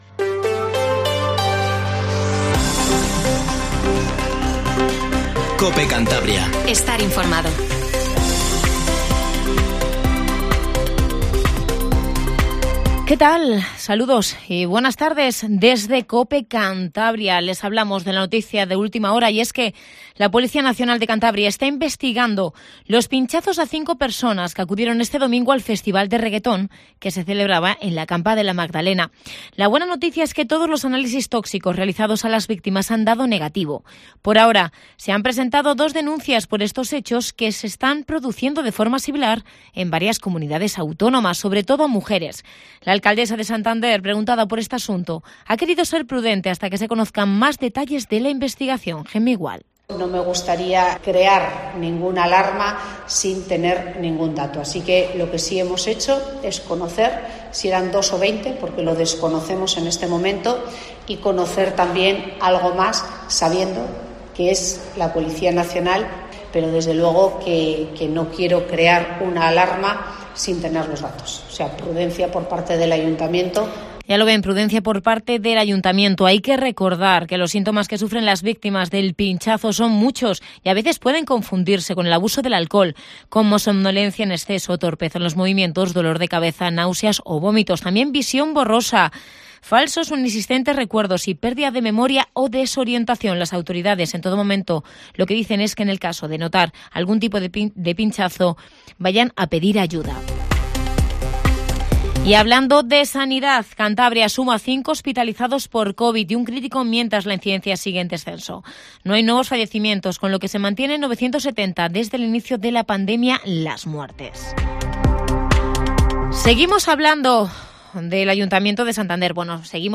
informativo regional 14:20